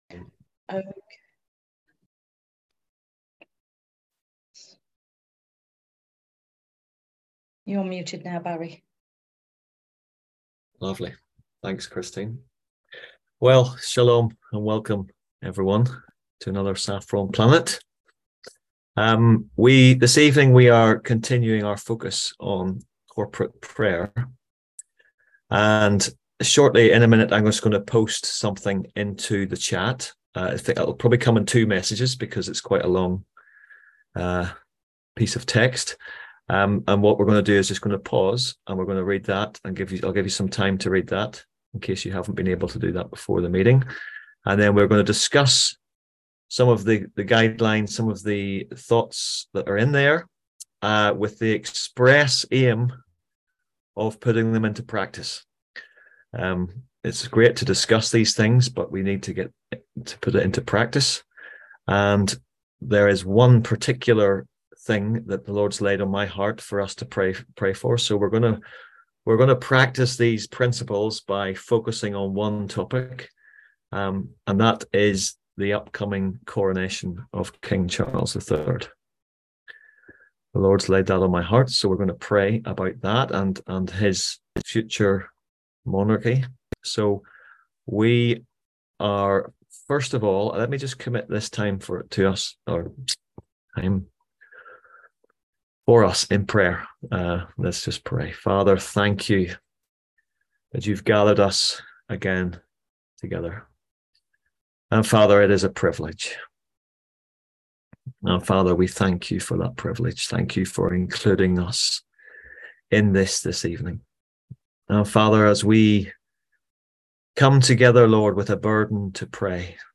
On April 17th at 7pm – 8:30pm on ZOOM ASK A QUESTION – Our lively discussion forum.
On April 17th at 7pm – 8:30pm on ZOOM